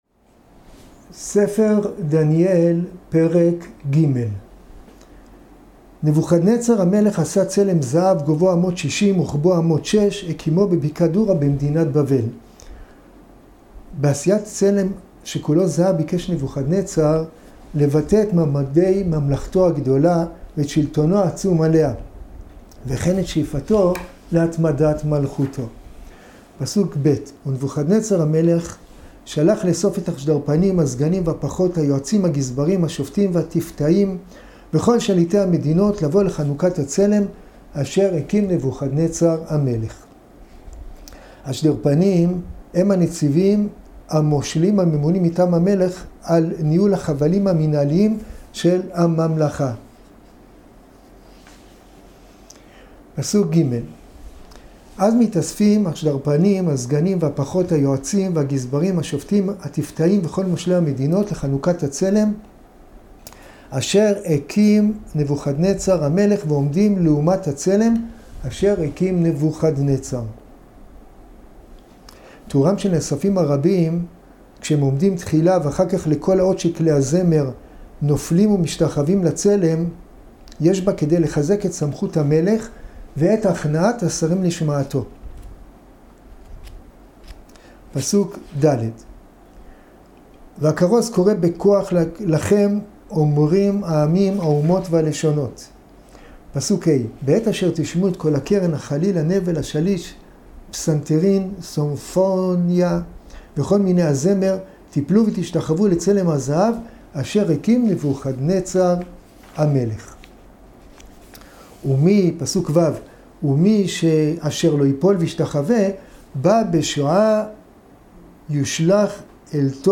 שיעורים בתנ"ך - ספר דניאל ספר דניאל פרק א גלות יהויקים.